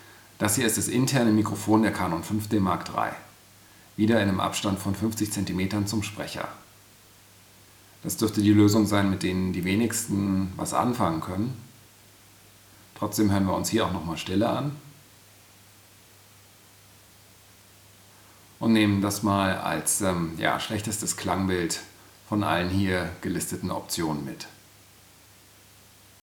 Internes Mikrofon der Canon EOS 5D Mark III
Als Referenz Hüllkurve zum Anlegen von externem Audio mag die On-Board Audioaufzeichnung der Canon 5D Mark III gerade noch dienen – für mehr jedoch nicht.
Canon5DMarkIII_direkt.aif